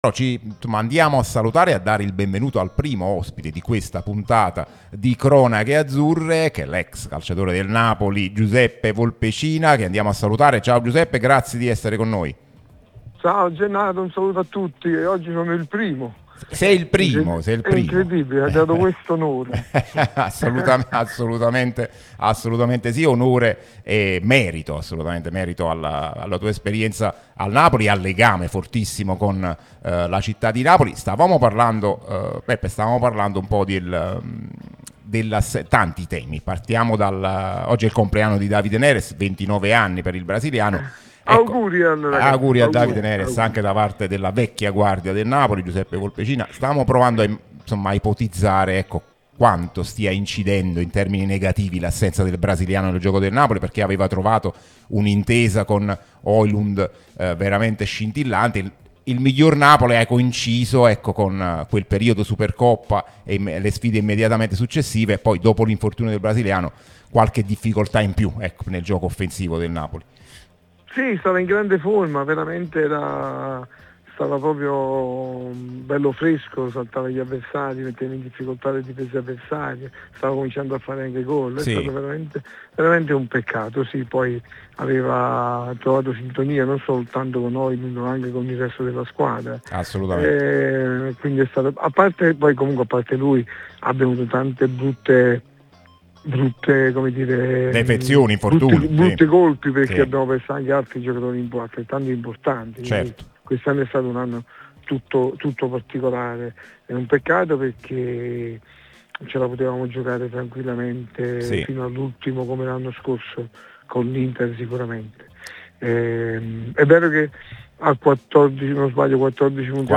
Ascolta l'audio L'ex azzurro Giuseppe Volpecina è intervenuto su Radio Tutto Napoli , prima radio tematica sul Napoli, che puoi seguire sulle app gratuite ( scarica qui per Iphone o per Android ), qui sul sito anche in video .